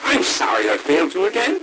A bit garbled, but yes.